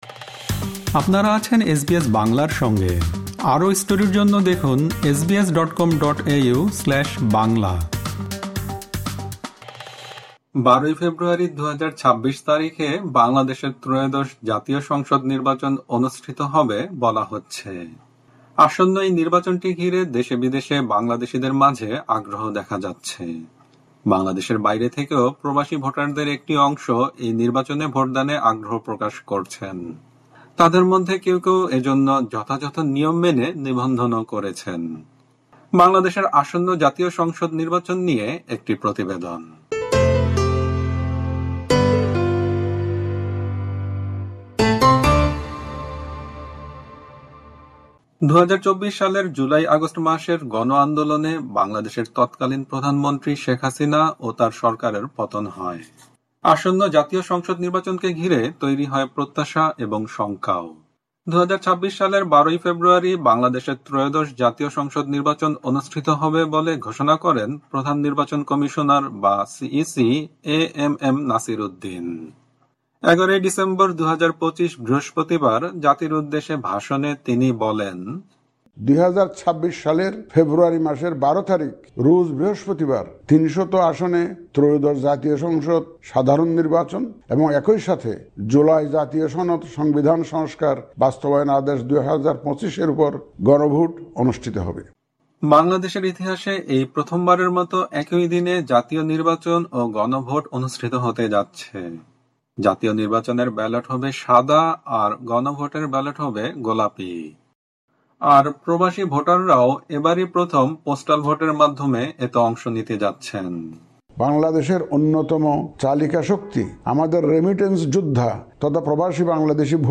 ১২ ফেব্রুয়ারি ২০২৬ তারিখে বাংলদেশের ত্রয়োদশ জাতীয় সংসদ নির্বাচন ও গণভোটকে ঘিরে দেশে-বিদেশে বাংলাদেশীদের মাঝে আগ্রহ দেখা যাচ্ছে। অস্ট্রেলিয়া থেকে পোস্টাল ভোটের জন্য নিবন্ধনকারীদের কয়েকজন কথা বলেছেন এসবিএস বাংলার সঙ্গে।